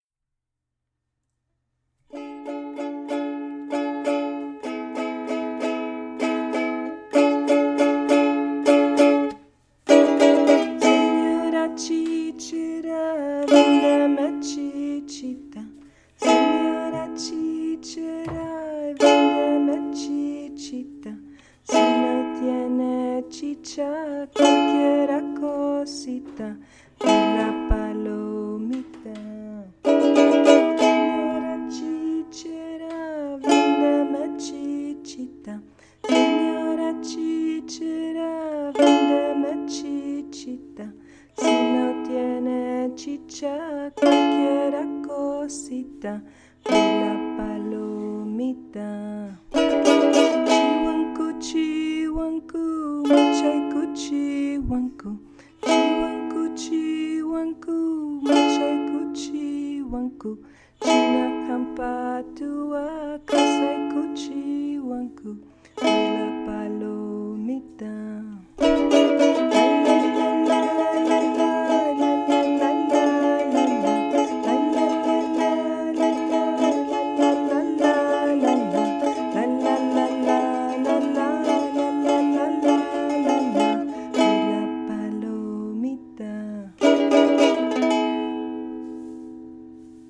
Es muy fácil de tocar, conseguí imitarla con los acordes LA, RE y MI menor, (Am, Dm and Em) lo cual encuentro que pueden ser tonos demasiado serios para los niños más pequeños.